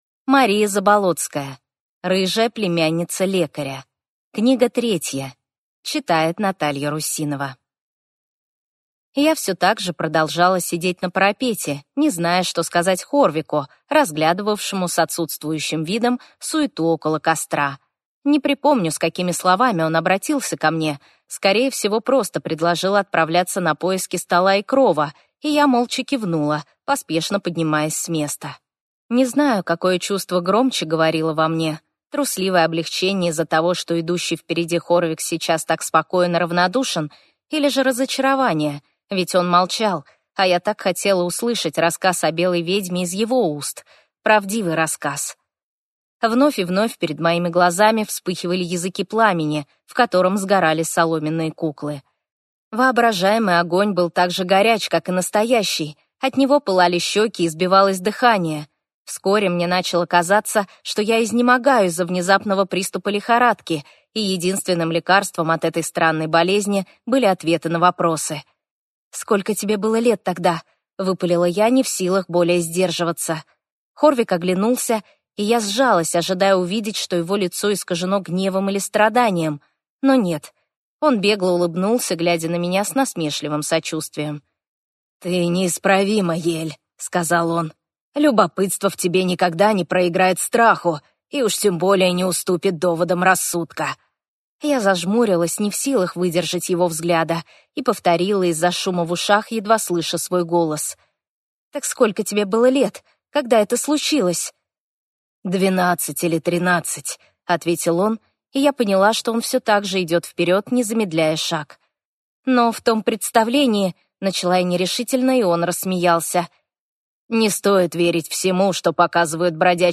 Аудиокнига Рыжая племянница лекаря-3 | Библиотека аудиокниг